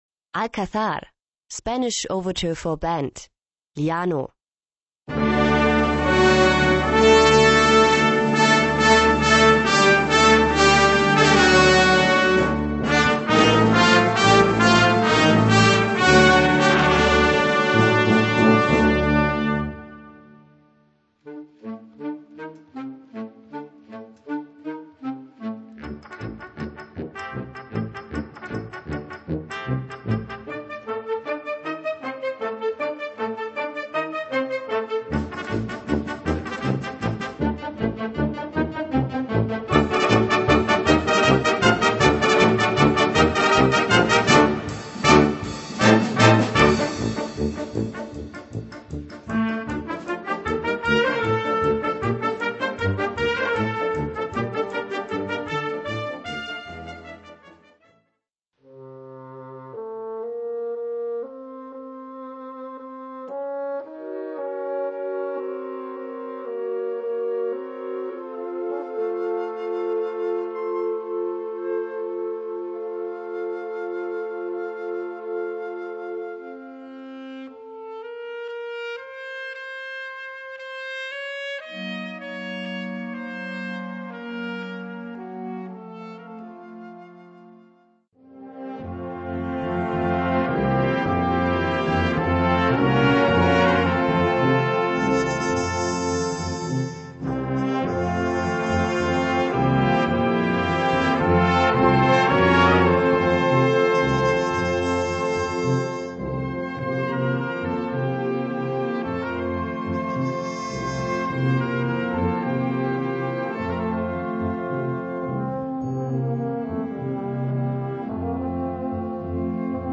Gattung: Spanish Overture for Band
Besetzung: Blasorchester